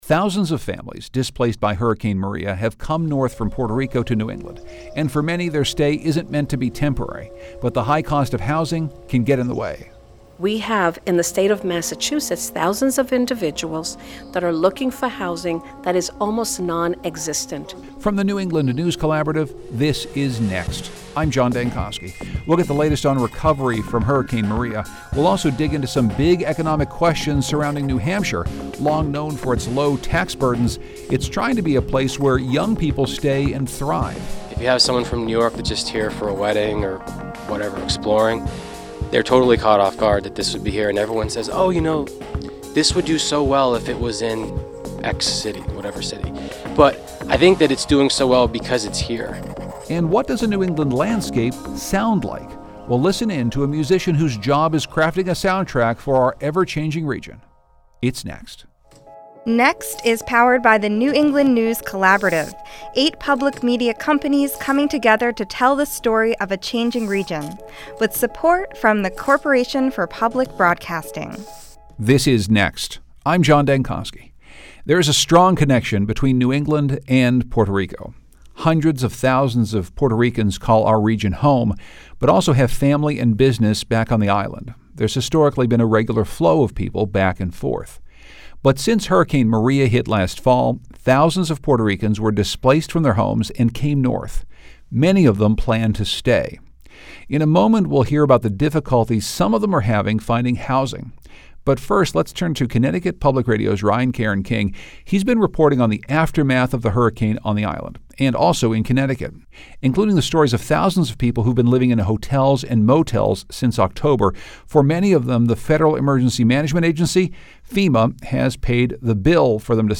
Plus, a musician who takes inspiration from the land itself joins us in studio.